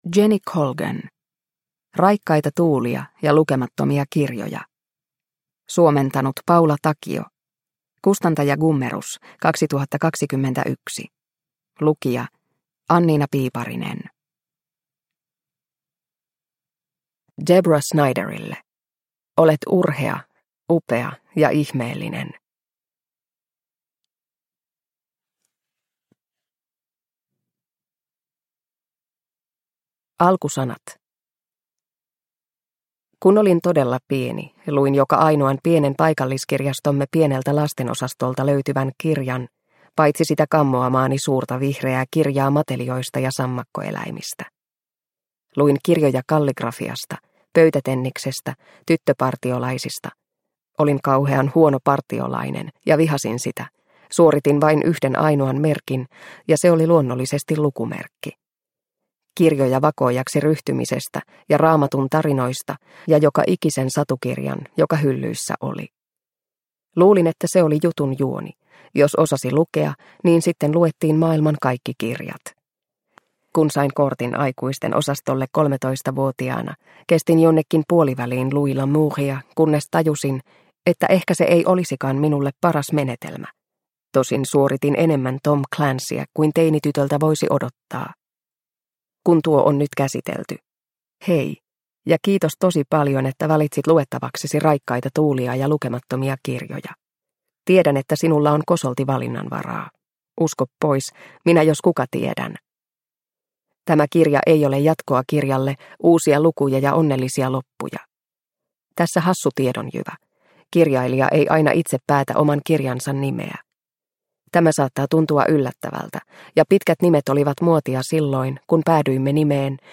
Raikkaita tuulia ja lukemattomia kirjoja – Ljudbok – Laddas ner